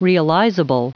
Prononciation du mot realizable en anglais (fichier audio)
Prononciation du mot : realizable